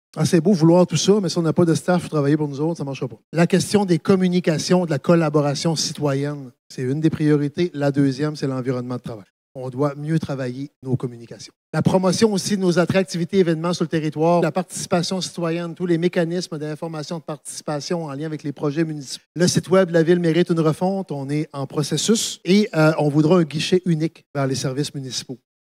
Au niveau de l’appareil municipal, la planification stratégique se concentre sur deux grands aspects, soit la construction et le maintien d’un environnement de travail sain, ainsi qu’un espace de communications plus efficace. Selon Daniel Côté, il est essentiel que les différents départements se parlent plus et que la Ville de Gaspé optimise ses interactions avec ses citoyens :